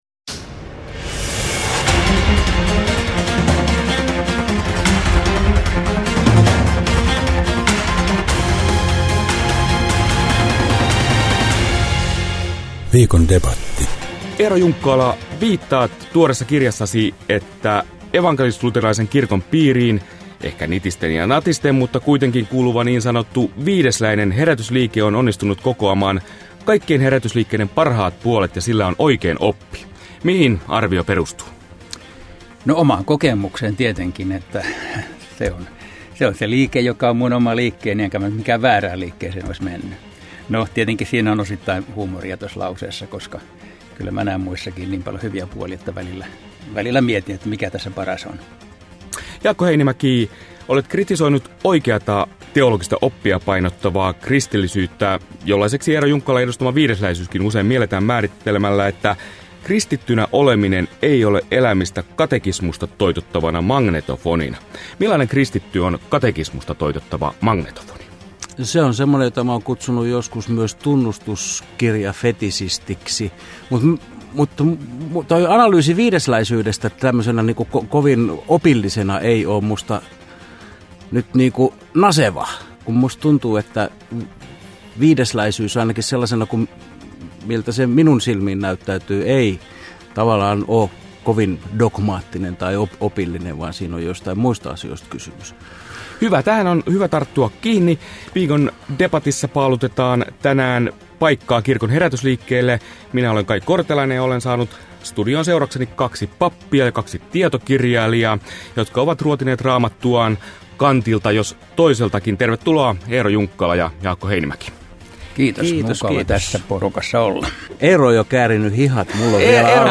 Viikon debatissa herätysliikkeiden paikan nykykirkossa paaluttaa kaksi pappia ja tietokirjailijaa